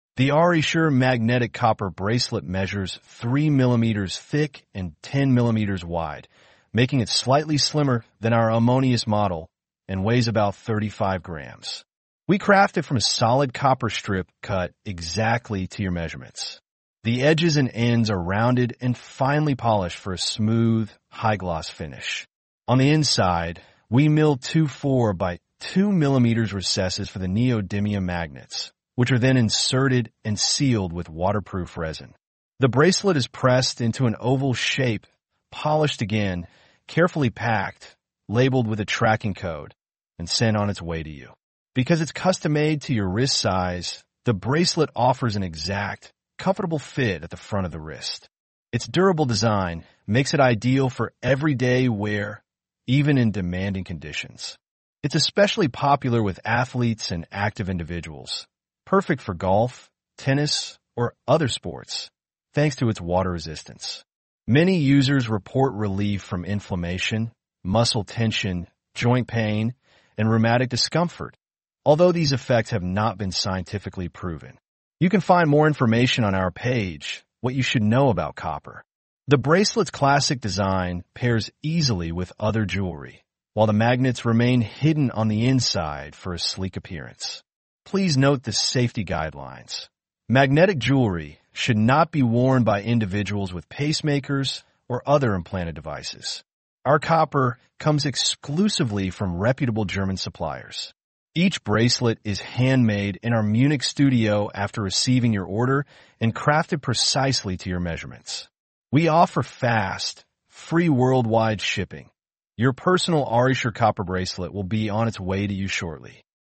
Arishi-engl-rttsreader-1.mp3